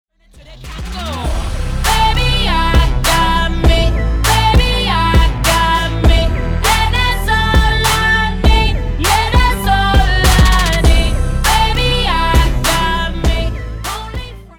• Качество: 320, Stereo
громкие
приятные
качающие
RnB